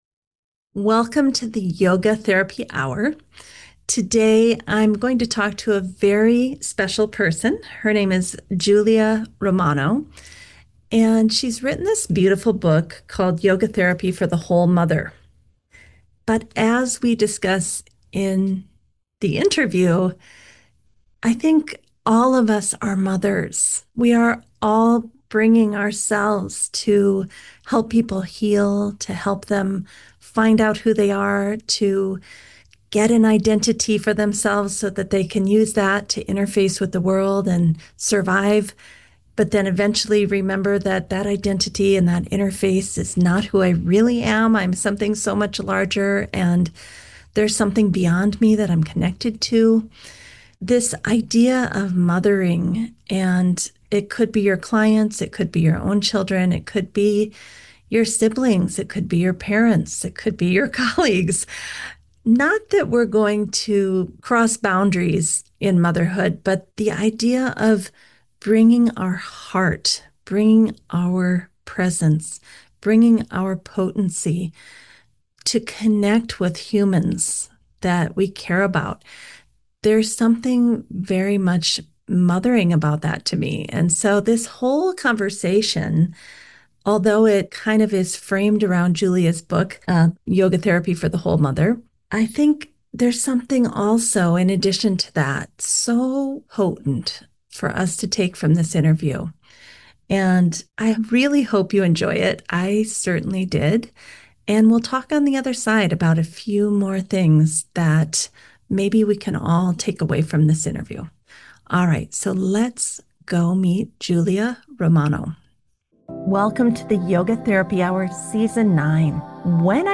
Mothering as a Universal Path: A Conversation